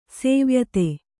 ♪ sēvyate